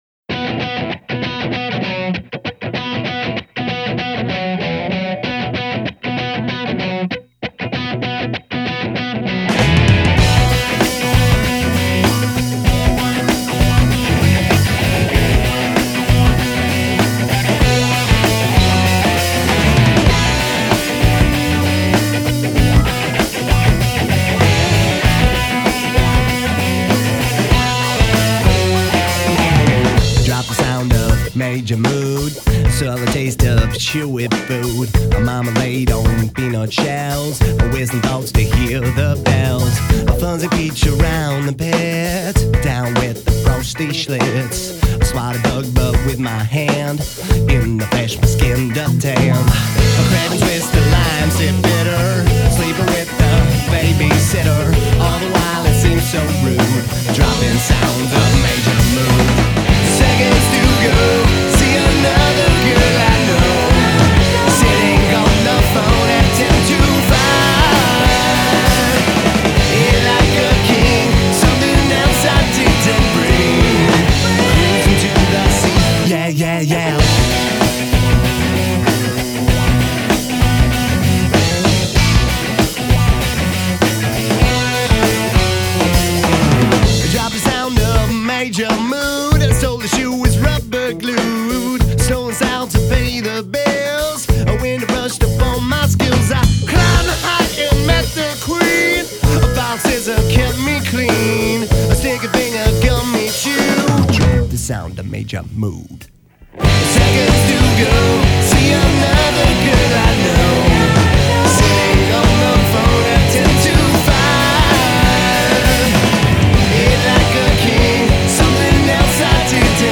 10 tracks of cubicle rock plus a special bonus track...